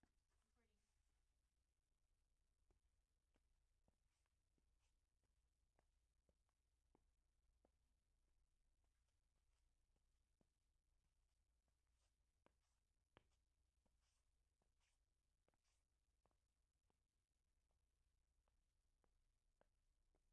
鞋子走路的瓷砖 Stop03
Tag: 脚步 步骤 瓷砖 地板 散步 弗利